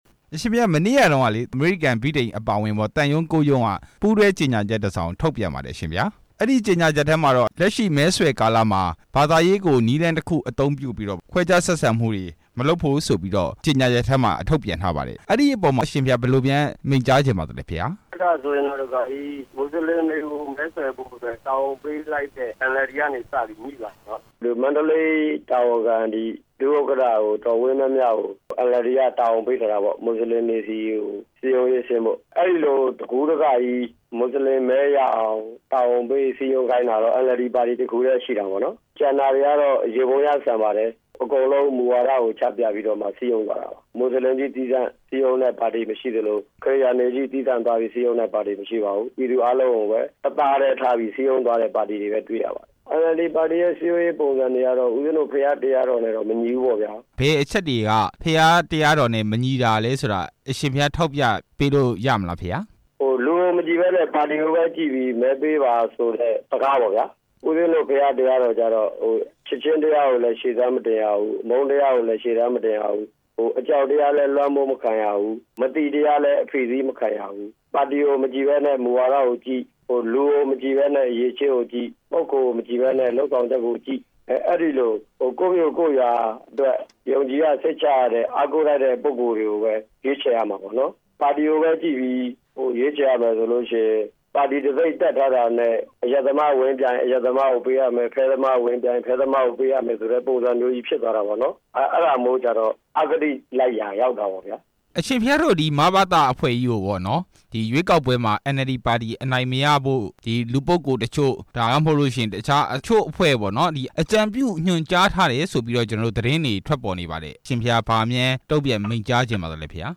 ဆရာတော် ဦးဝီရသူနဲ့ မေးမြန်းချက်